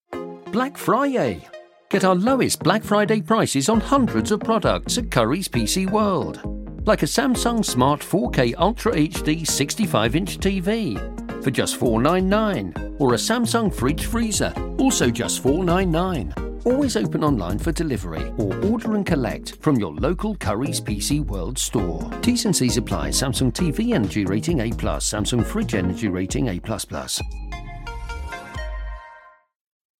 one ad signalled that there were five days left in the sale, with details of two products on offer to all in Doncaster, whilst another ad counted down the two days left and featured different products that were available in Aberystwyth.